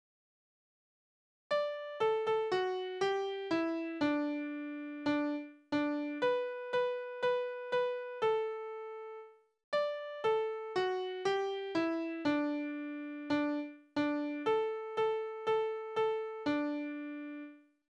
Trinklieder
Tonart: D-Dur
Taktart: 4/4
Tonumfang: Oktave